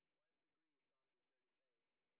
sp05_train_snr10.wav